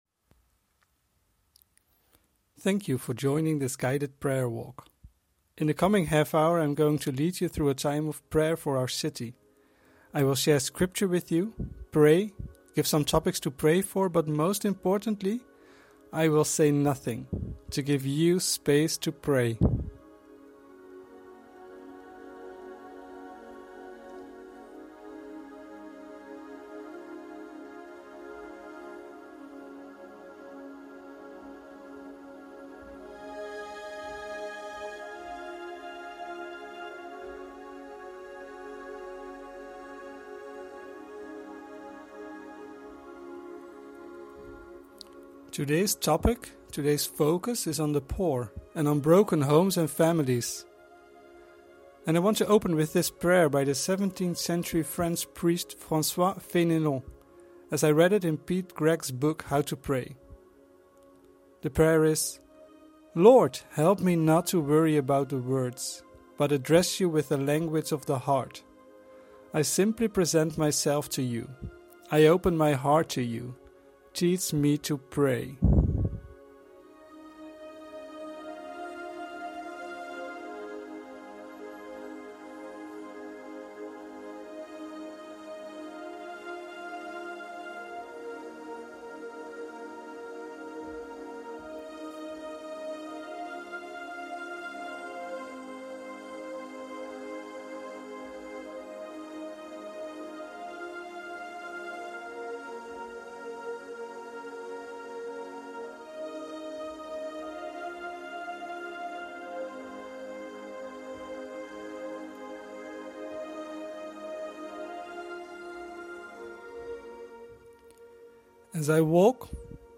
Guided Prayer Walks | Week 3 | Topic: The Poor & Broken homes an families